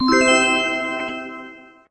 magic_harp_5.ogg